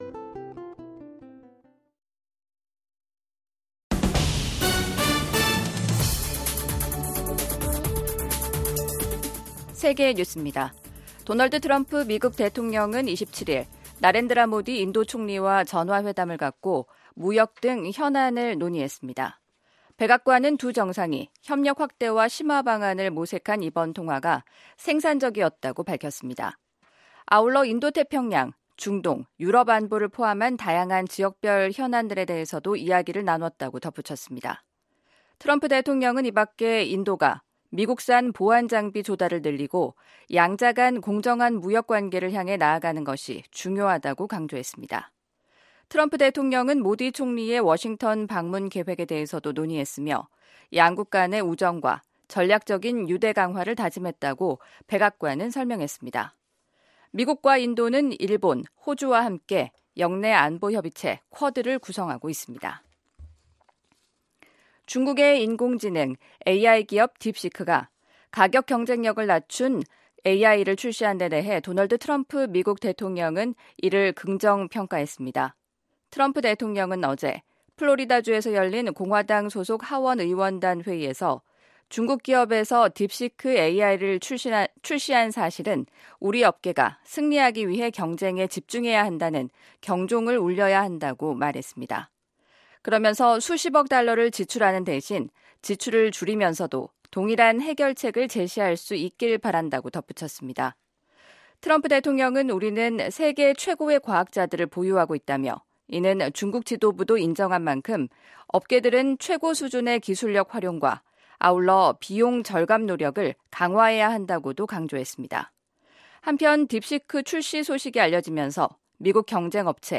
VOA 한국어 간판 뉴스 프로그램 '뉴스 투데이', 2025년 1월 28일 2부 방송입니다. 한국과 쿠바 두 나라는 수교 11개월만에 대사관 개설과 대사 부임 절차를 마무리했습니다. 트럼프 대통령이 북한을 ‘핵 보유국’이라고 지칭한 이후 한국 내에서 커지고 있는 자체 핵무장론과 관련해 미국 전문가들은 미한동맹과 역내 안정에 부정적인 영향을 미칠 것이라고 전망했습니다.